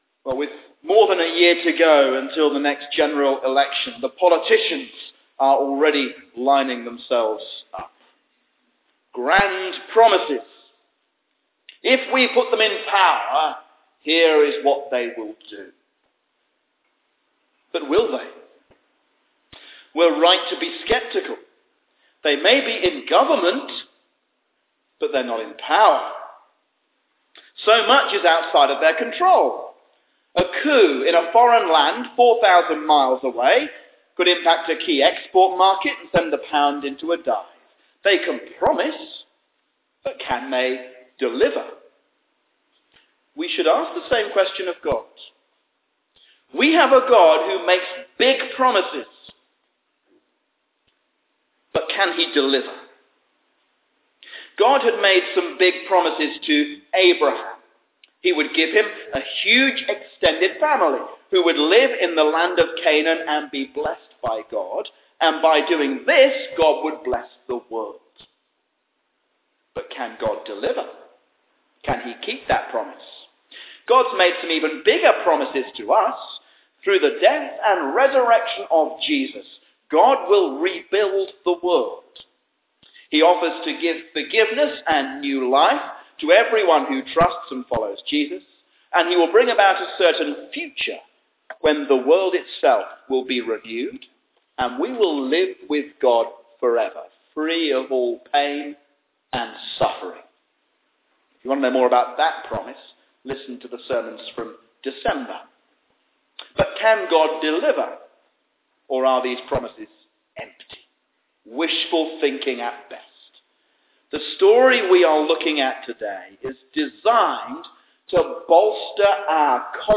A sermon on Genesis 24